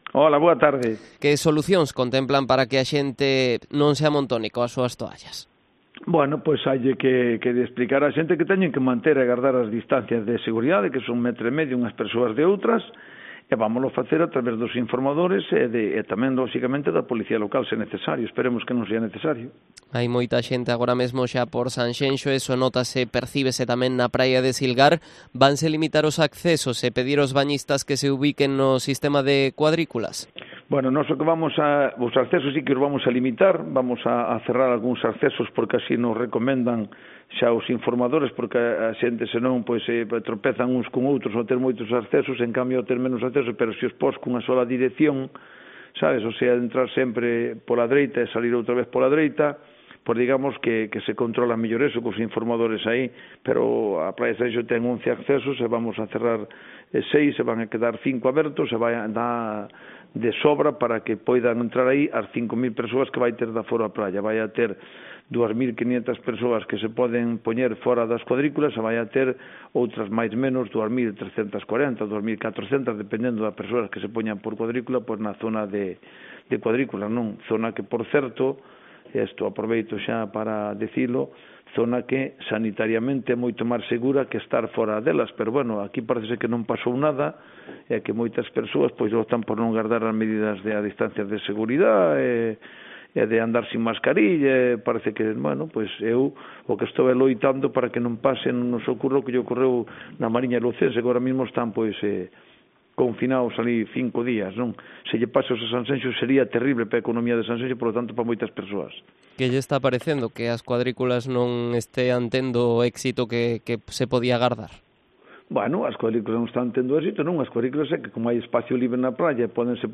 Entrevista al alcalde de Sanxenxo, Telmo Martín, en Cope Pontevedra